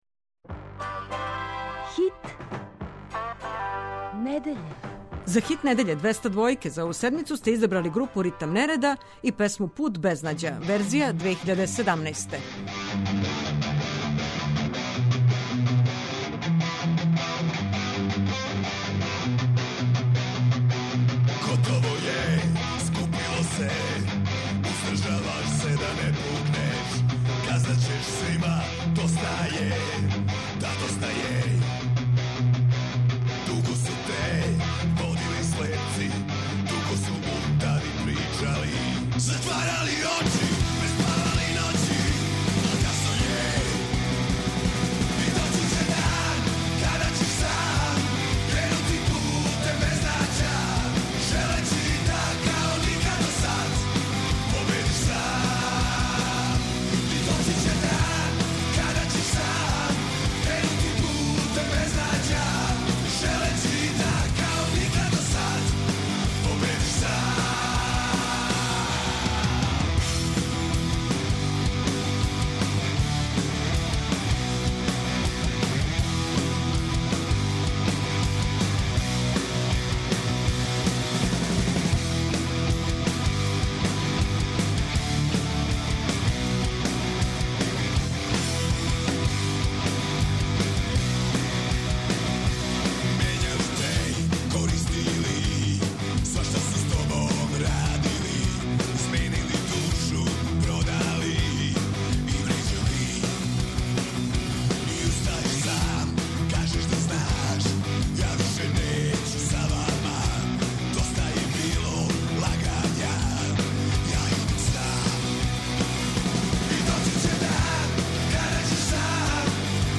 Као и сваке суботе и ове је на програму Финале Хита недеље. Пролази 15 песама у три категорије.